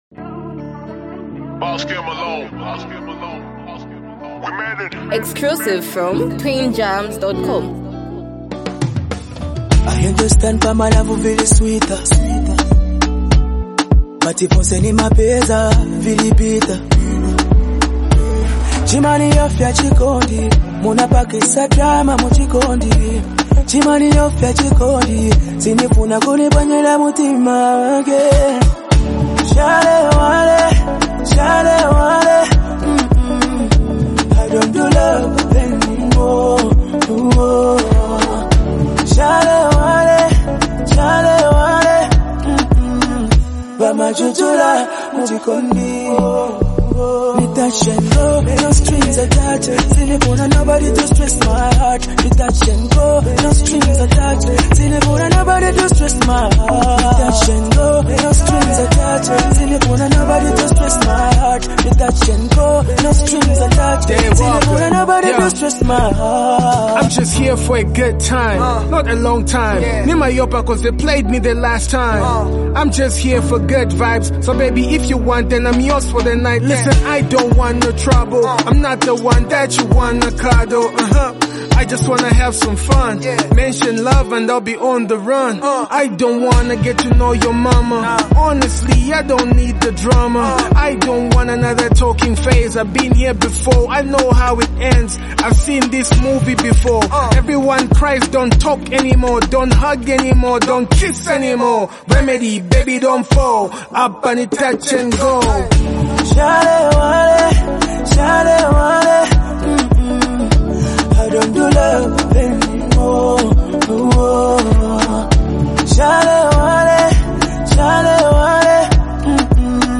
leads the track with smooth and catchy vocals